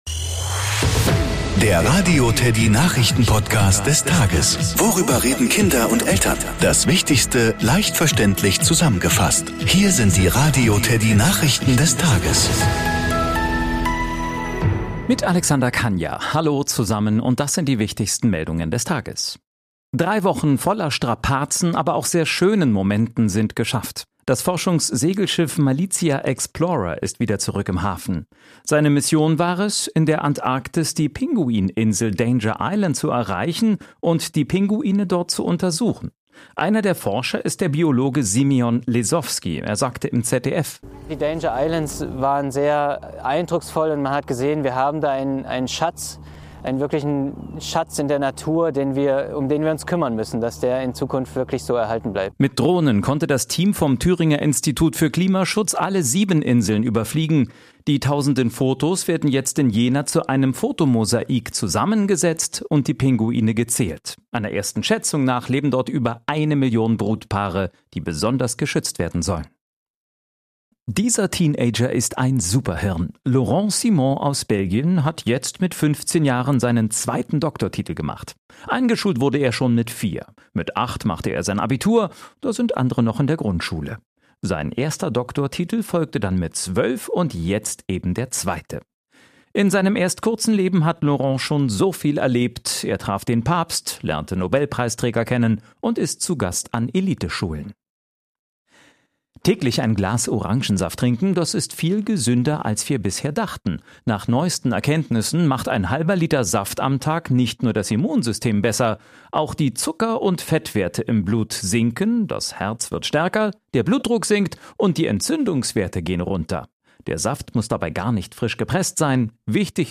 Nachrichten , Kinder & Familie